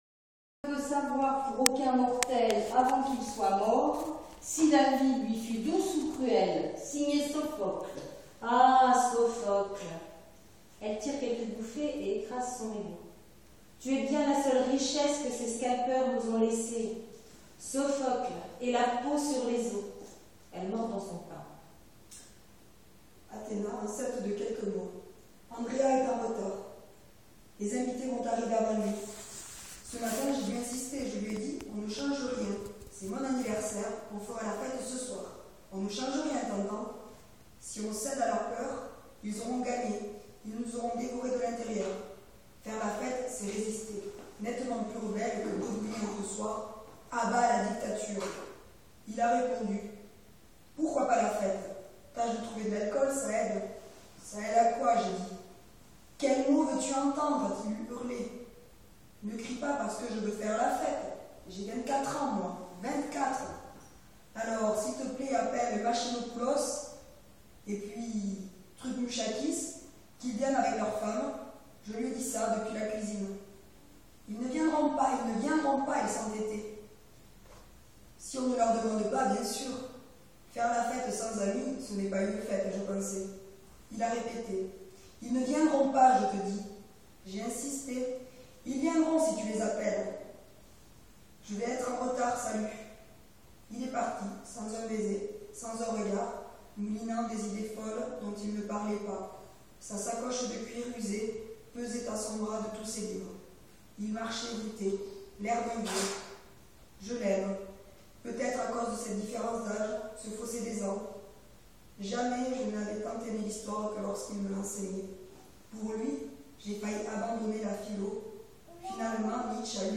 PREMIERE LECTURE DE LA PIECE Les Pav�s de Syntagma
lue par les �l�ves de l'Atelier T2A